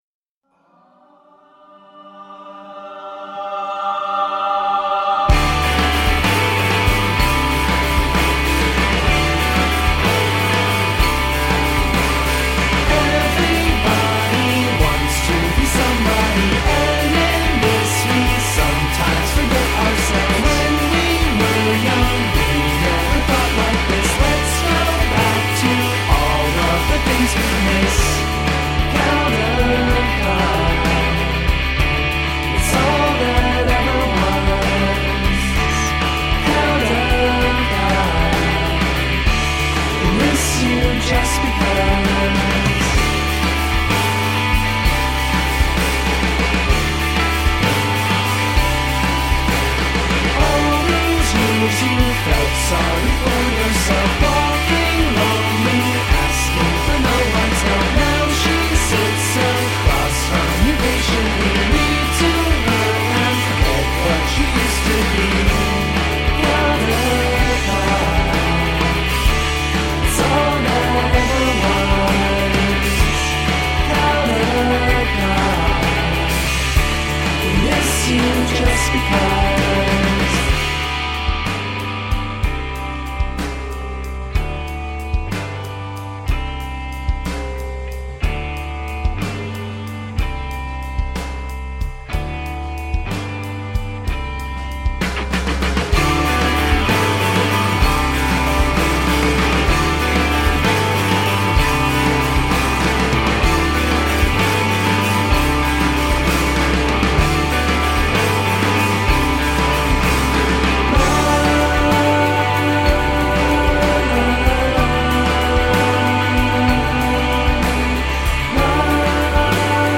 Sophisticated rock with emo undercurrents.